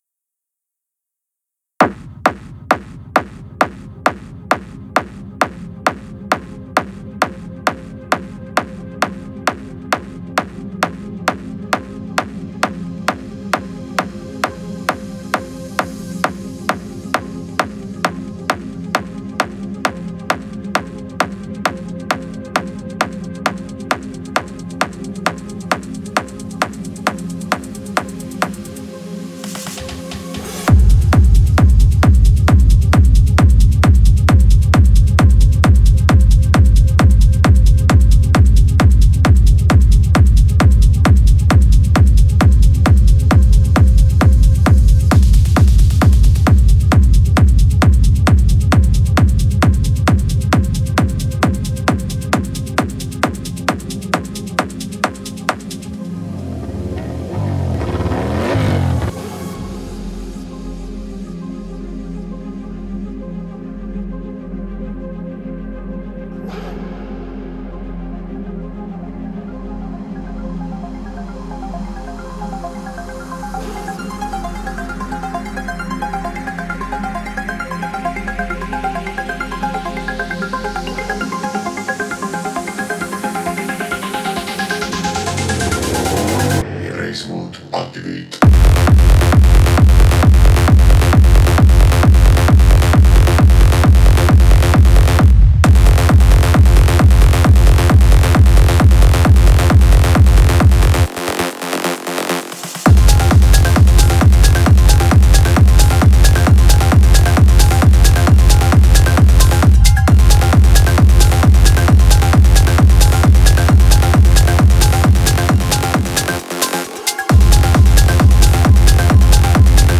Type: Serum Samples
Deep House Electro House Future House Hardcore / Hardstyle Industrial Melodic Techno Phonk house Progressive House Tech House Techno Trap Tropical House
Dive into punchy drums, irresistible basslines, captivating synths, and atmospheric textures.